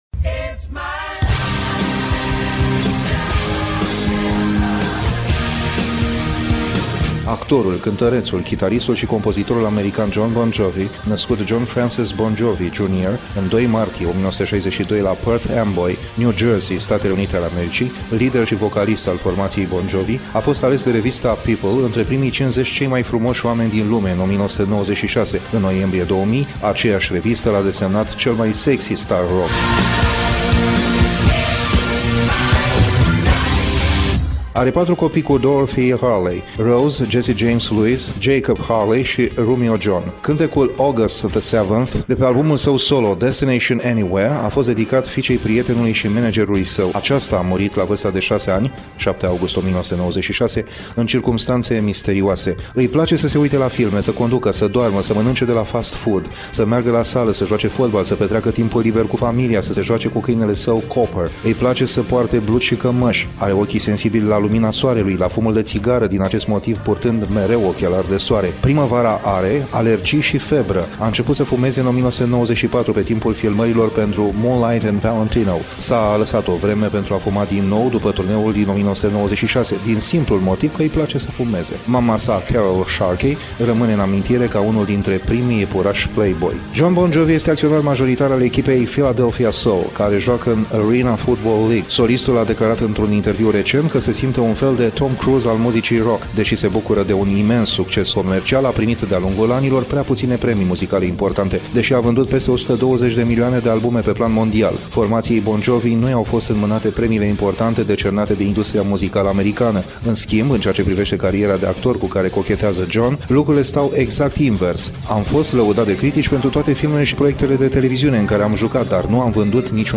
documentar